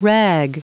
Prononciation du mot rag en anglais (fichier audio)
Prononciation du mot : rag